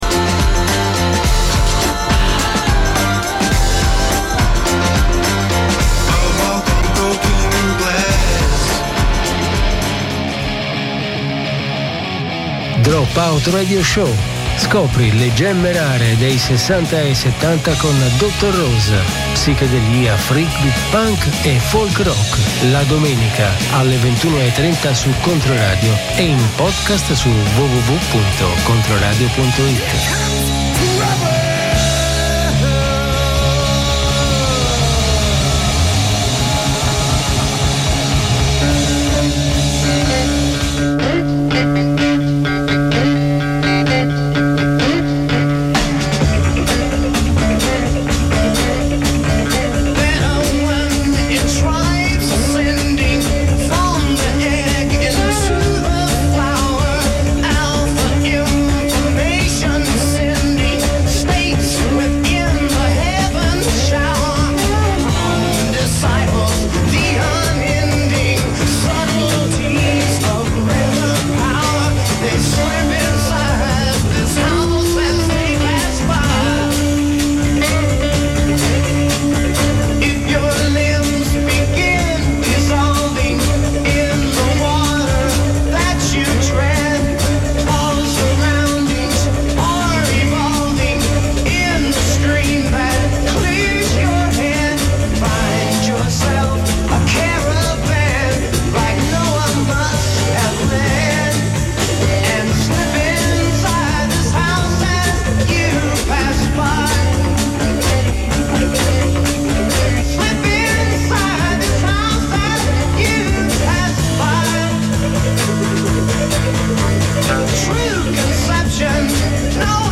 Scopri le gemme rare degli anni '60 e '70: psichedelia, freakbeat, punk e folk rock.